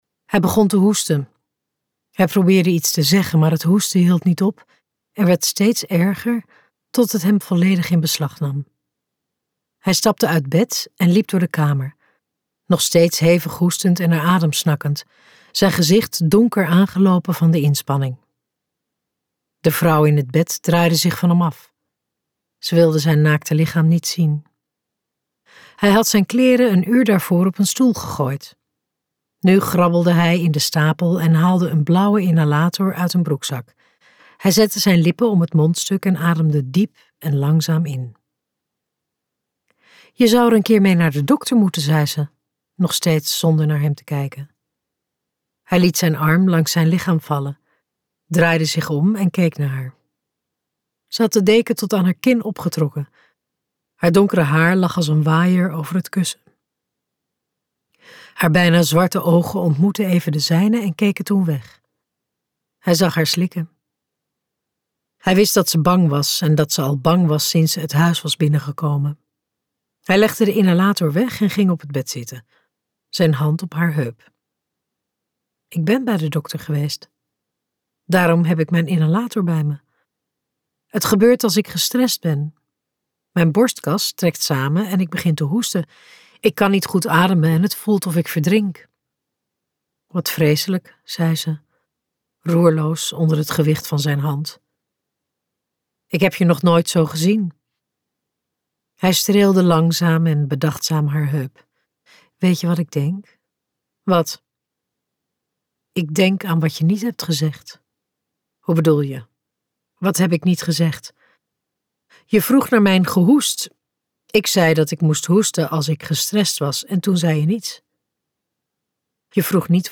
Ambo|Anthos uitgevers - Verkeerde afslag luisterboek